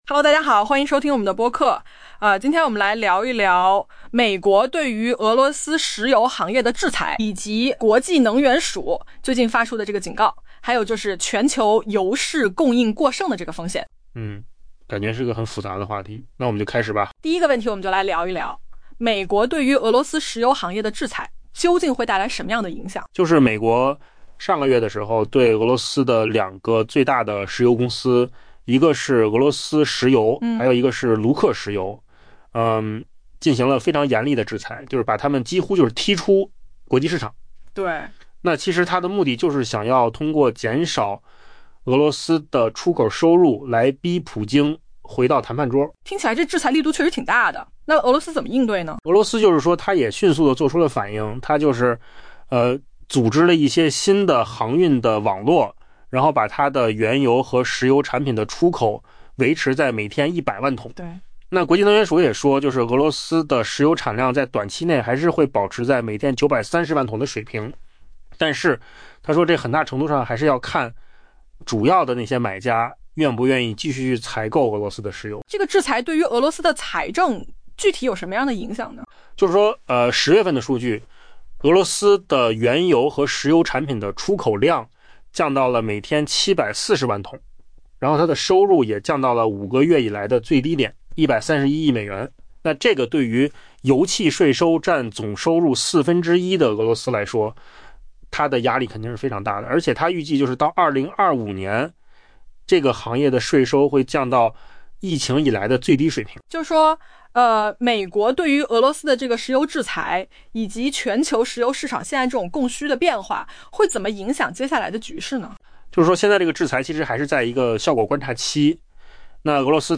AI 播客：换个方式听新闻 下载 mp3 音频由扣子空间生成 国际能源署 （IEA） 表示， 美国对俄罗斯实施的制裁对俄罗斯原油产量前景存在 「可观的下行风险」 ，但在看到更多执行细节之前，该机构暂不估计具体影响。